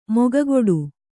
♪ mogagoḍu